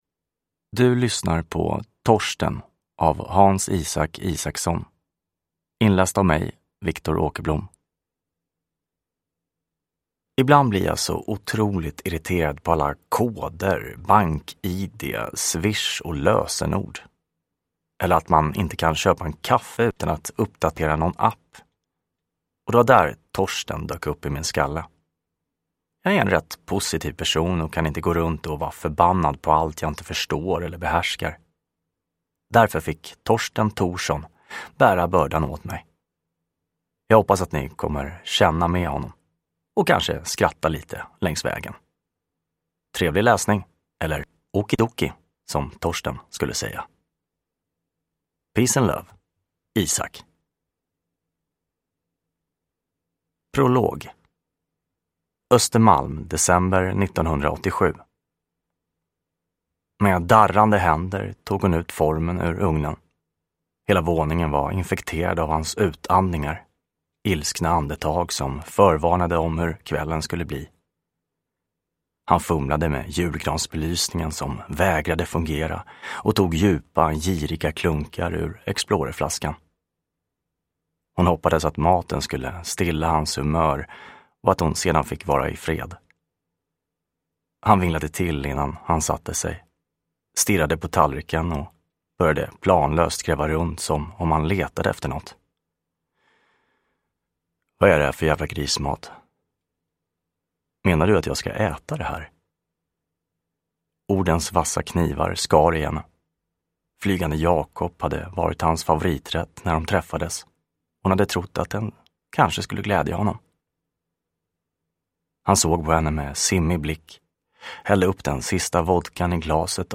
Thorsten – Ljudbok